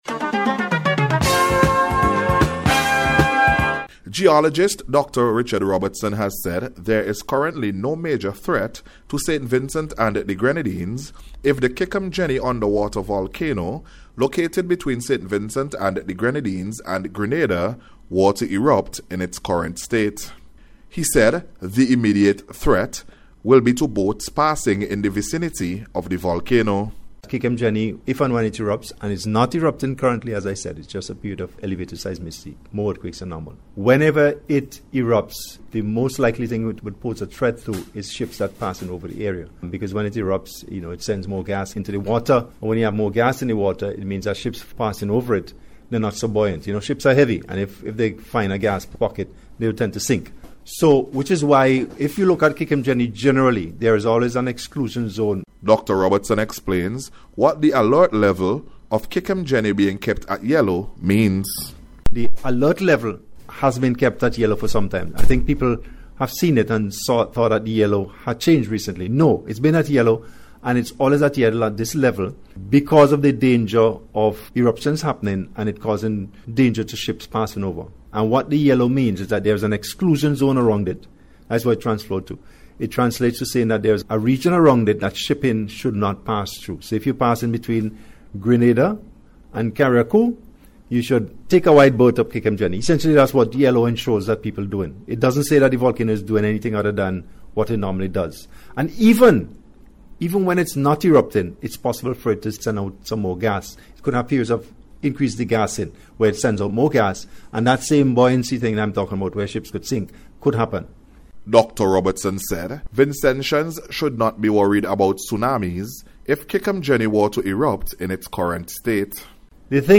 NBC’s Special Report – Monday September 1st 2025